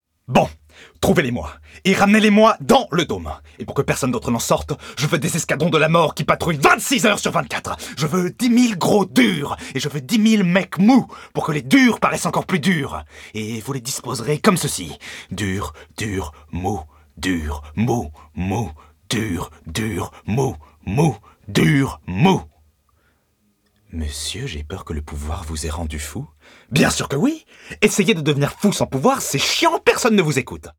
15 - 60 ans - Baryton